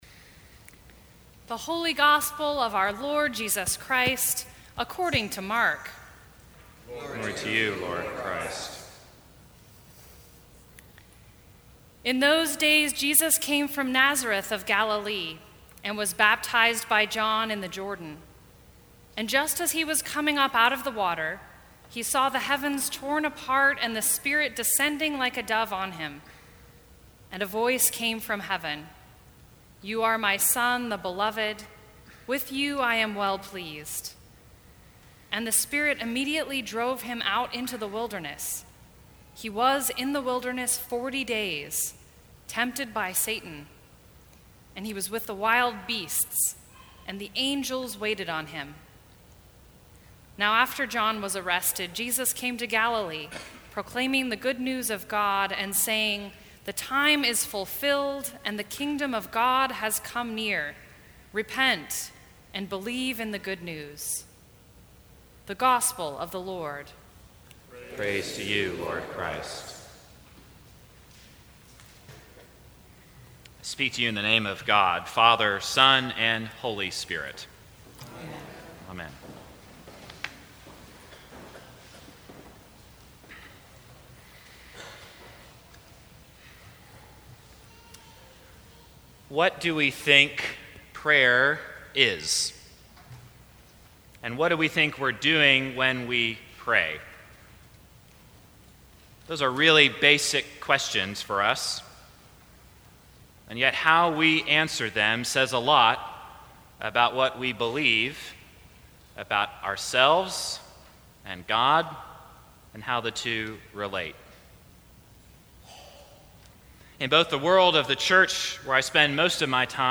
Sermons from St. Cross Episcopal Church Resisting the Temptation to Disengage Feb 18 2018 | 00:18:24 1x 00:00 / 00:18:24 Subscribe Share Apple Podcasts Spotify Overcast RSS Feed Share Link Embed